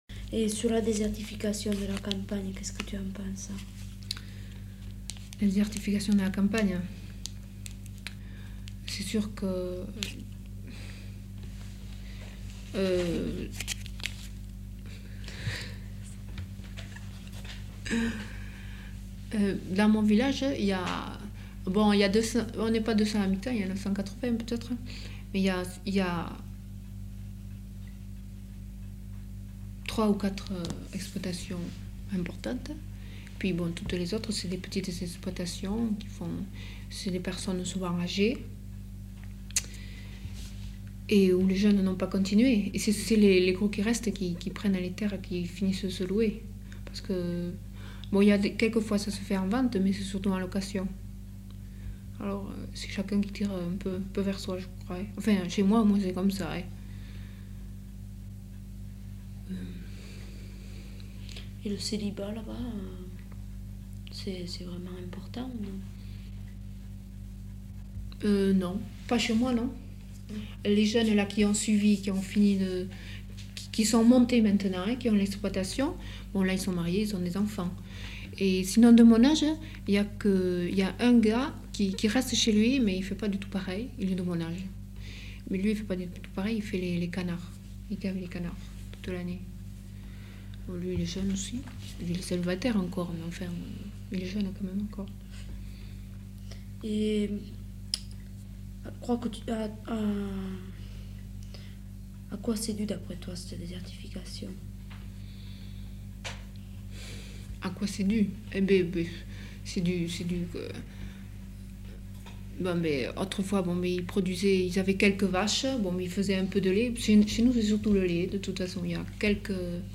Aire culturelle : Béarn
Lieu : Samatan
Genre : témoignage thématique